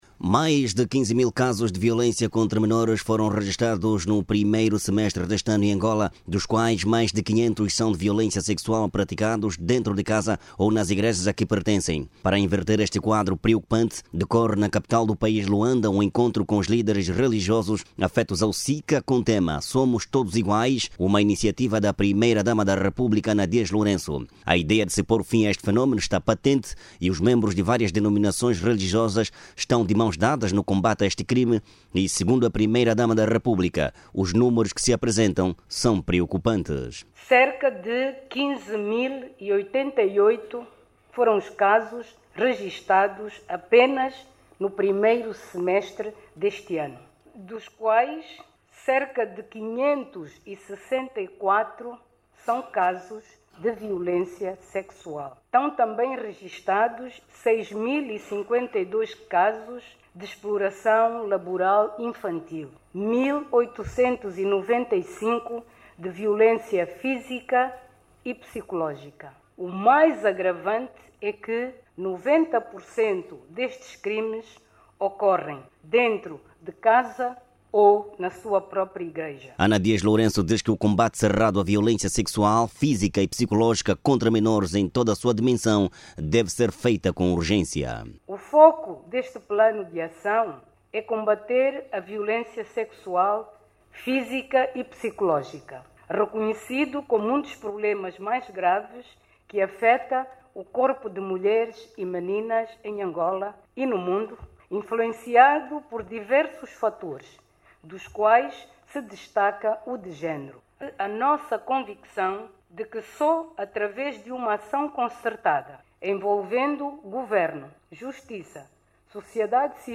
Ana Dias Lourenço falava esta terça-feira, no workshop sobre Educação para a Igualdade de Género e a Luta contra a Violência Infanto-Juvenil, realizado pela Fundação Ngana Zenza, no âmbito da campanha “Somos Todos Iguais”.